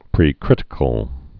(prē-krĭtĭ-kəl)